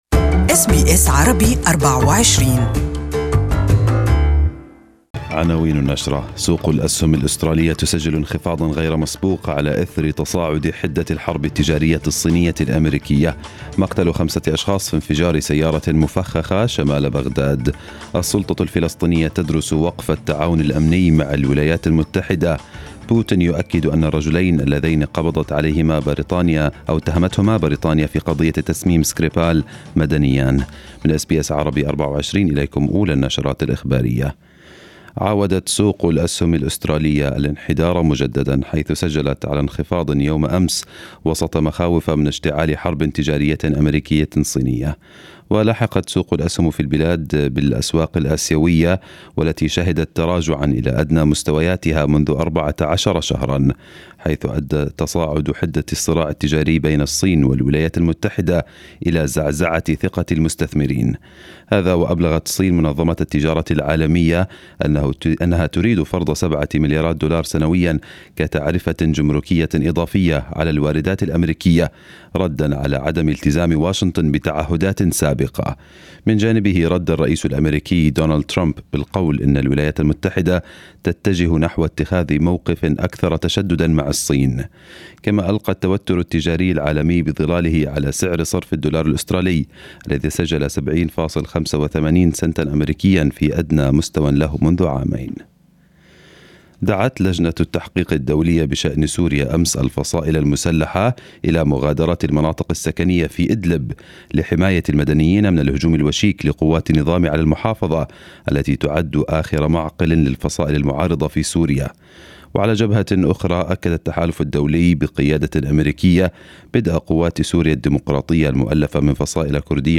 First news bulletin in the day in Arabic